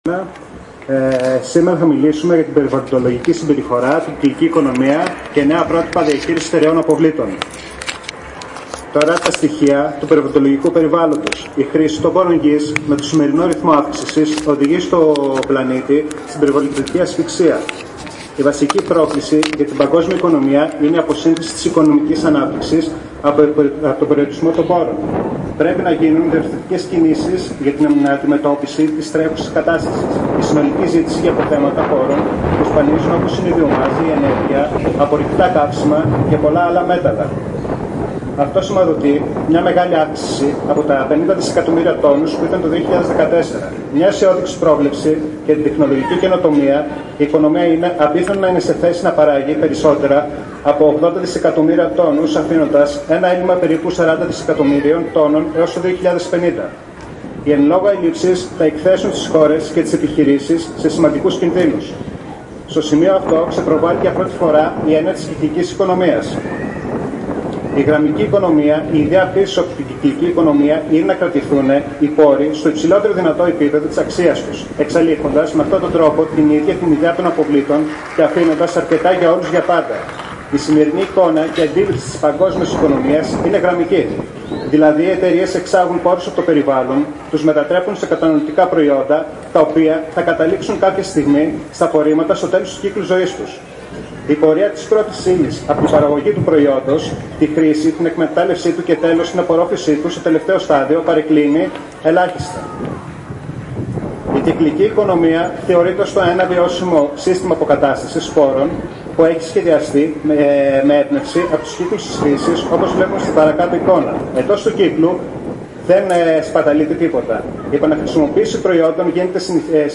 Η εκδήλωση στην Κεφαλονιά πραγματοποιήθηκε την Πέμπτη 2 Ιουνίου στις 19.30 έμπροσθεν της Περιφερειακής Ενότητας Κεφαλληνίας στο Αργοστόλι.